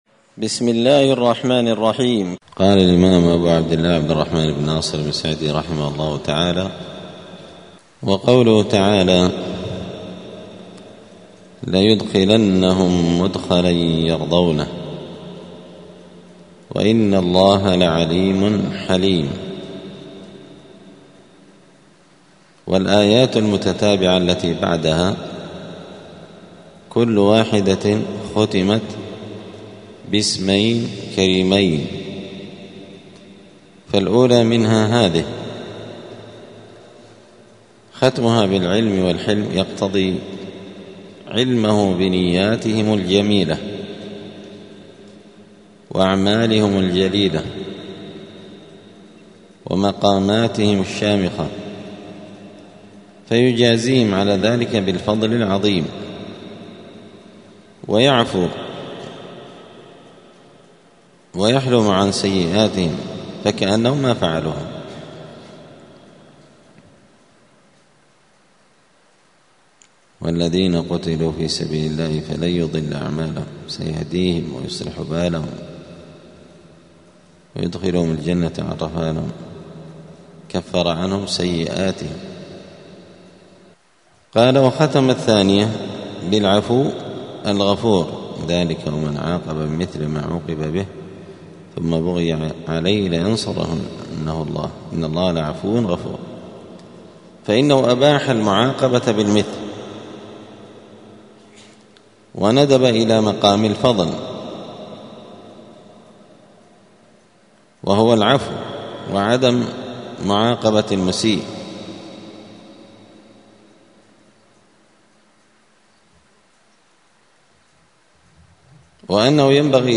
دار الحديث السلفية بمسجد الفرقان قشن المهرة اليمن
25الدرس_الخامس_والعشرون_من_كتاب_القواعد_الحسان.mp3